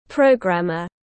Lập trình viên tiếng anh gọi là programmer, phiên âm tiếng anh đọc là /ˈprəʊɡræmər/.
Programmer /ˈprəʊɡræmər/